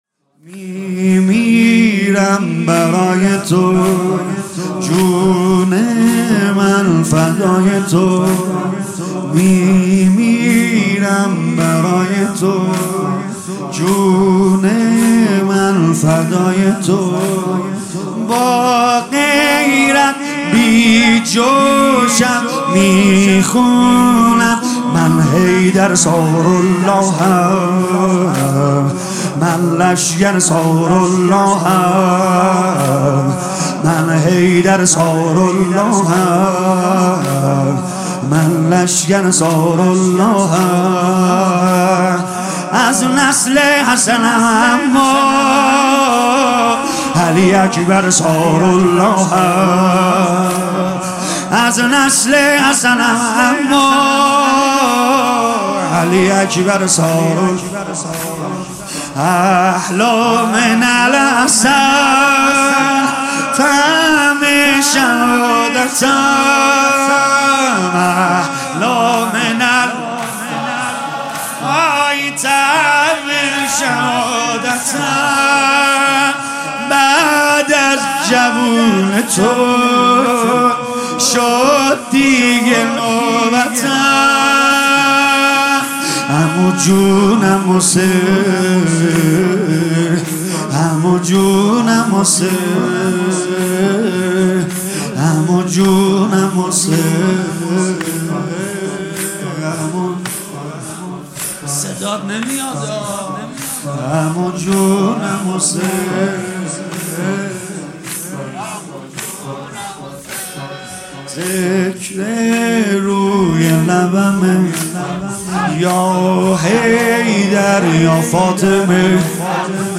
مداحی جدید سید رضا نریمانی شب پنجم محرم 1399هیات فداییان حسین(ع) اصفهان